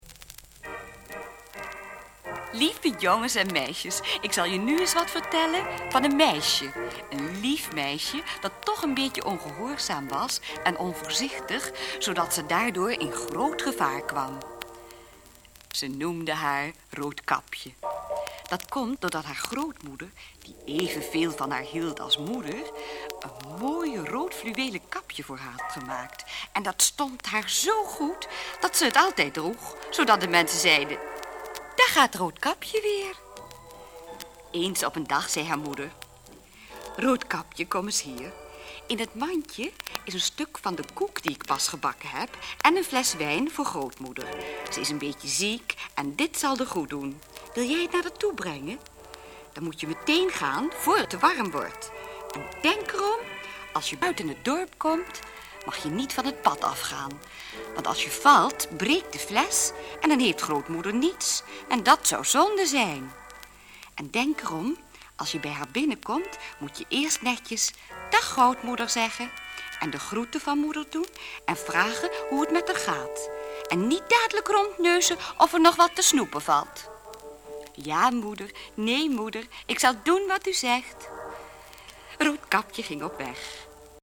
Sprookjesvertelling
De muzikale omlijsting is van Cor Steyn (orgel).